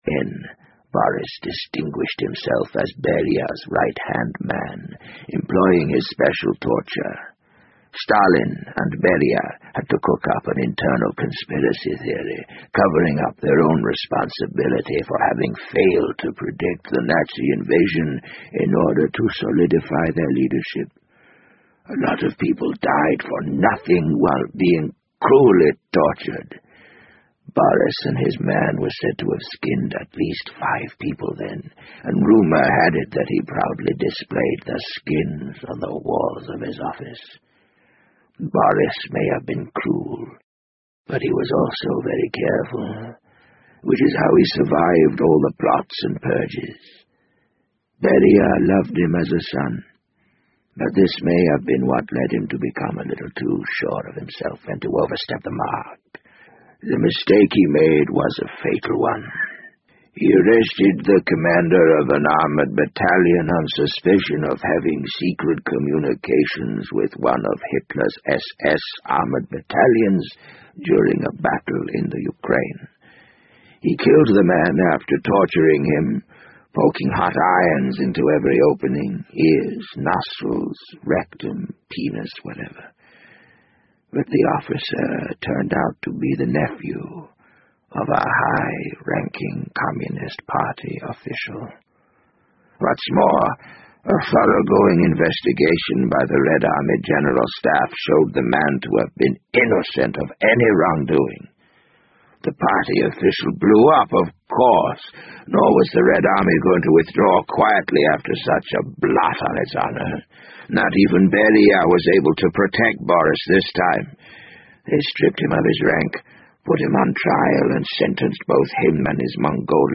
BBC英文广播剧在线听 The Wind Up Bird 014 - 6 听力文件下载—在线英语听力室